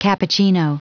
dicas de inglês, como pronunciar cappuccino em ingles significa cappuccino (bebida quente preparada com café e leite, aos quais se adicionam canela, chocolate ou chantili) como em Have a cappuccino and a sandwich while waiting for your next class (Tome um cappuccino e coma um sanduíche enquanto espera sua próxima aula).